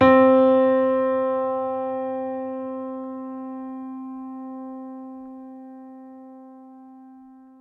piano-sounds-dev
c3.mp3